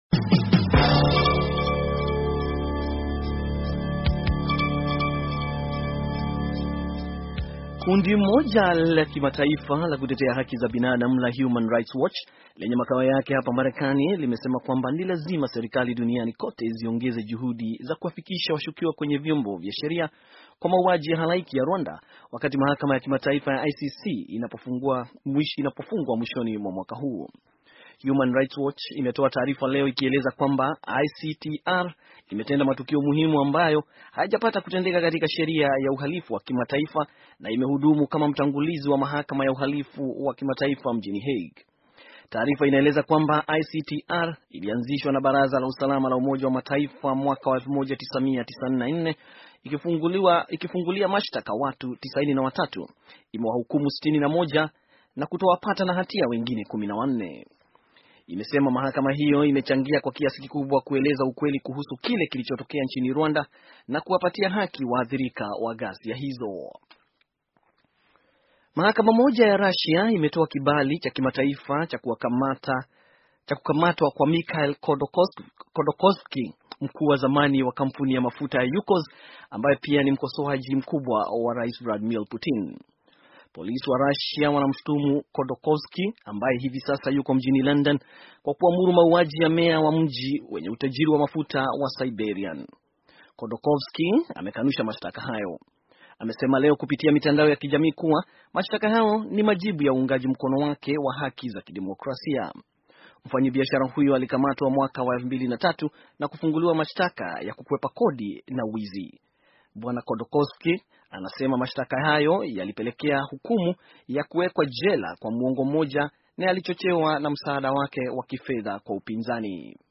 Taarifa ya habari - 5:18